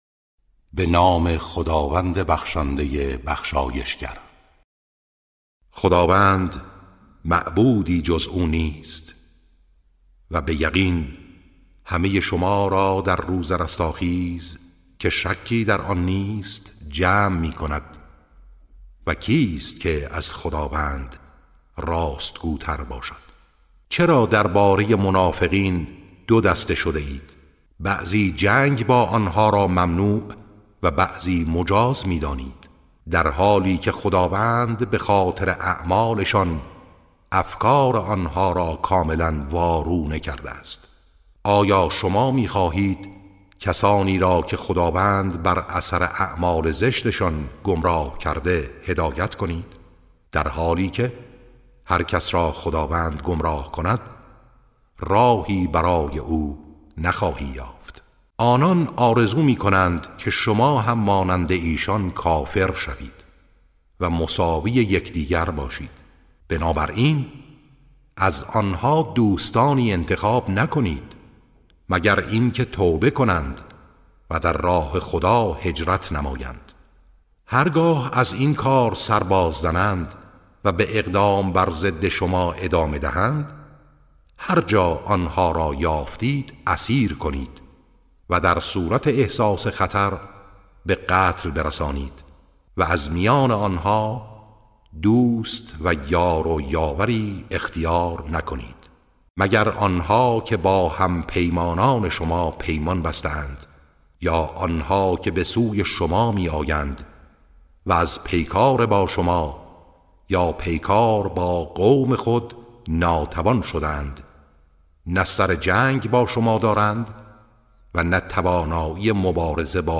ترتیل صفحه ۹۲ سوره مبارکه نساء(جزء پنجم)